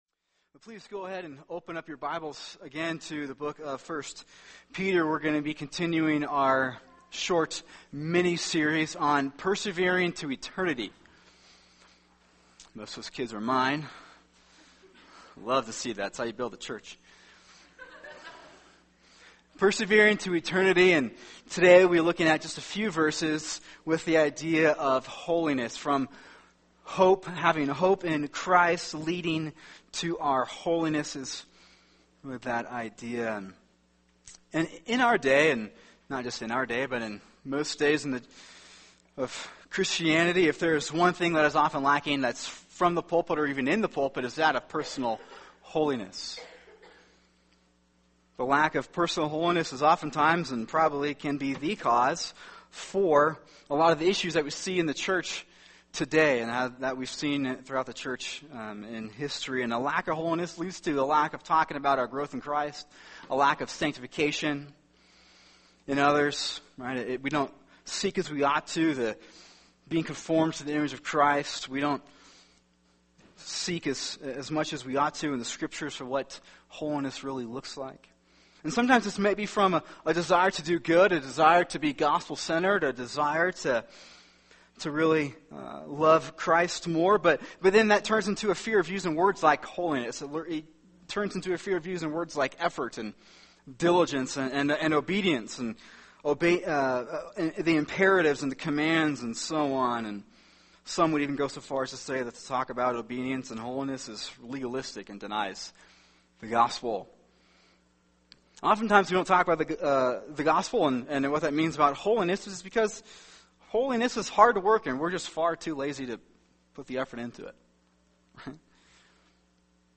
[sermon] 1 Peter 1:13-16 “From Hope to Holiness” | Cornerstone Church - Jackson Hole